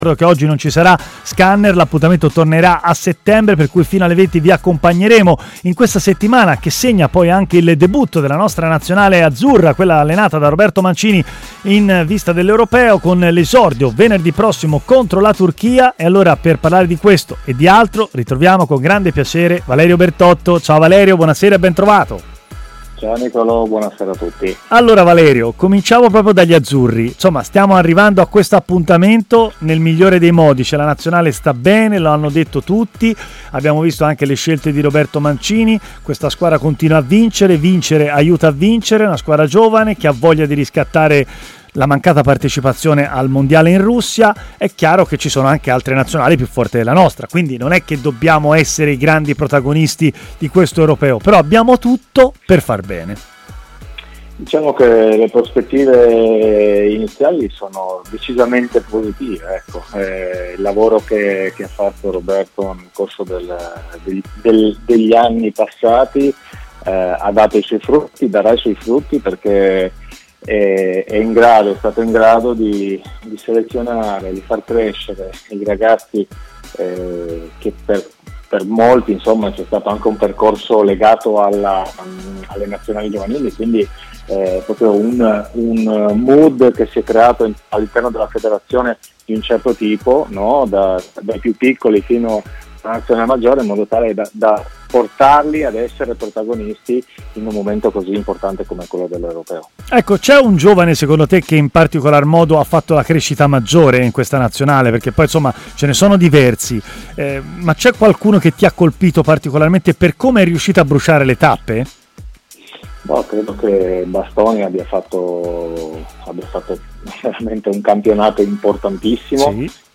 L'ex difensore Valerio Bertotto, oggi allenatore, ha parlato a Stadio Aperto, trasmissione di TMW Radio